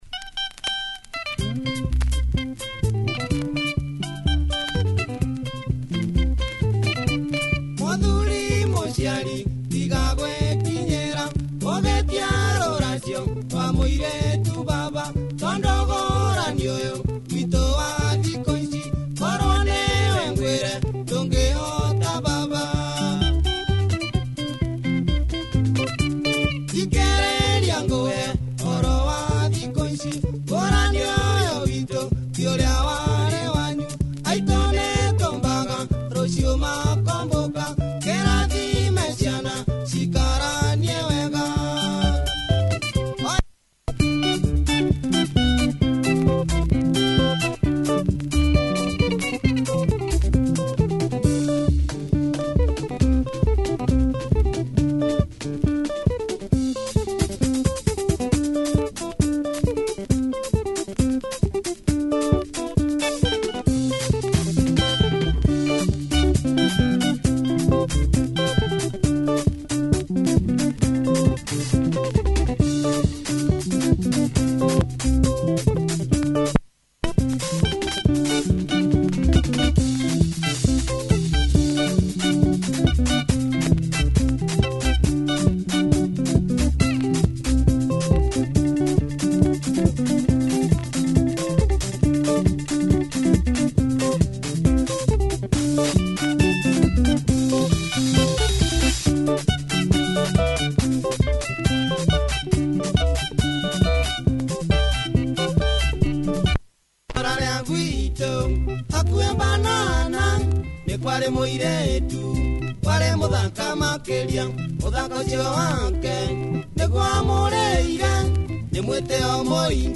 Nice Benga groover